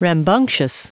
Prononciation du mot rambunctious en anglais (fichier audio)
Prononciation du mot : rambunctious